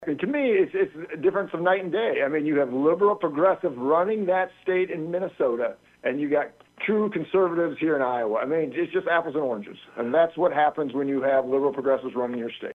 FEENSTRA SAYS LIKE MANY IOWANS, HE IS DISTURBED BY THE HEADLINES OF RAMPANT TAXPAYER FRAUD IN MINNESOTA: